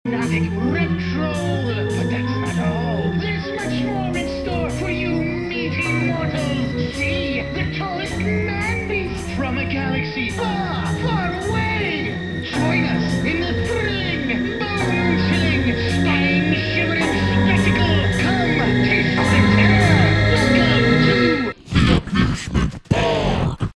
Category: Shock Rock/Horror Rock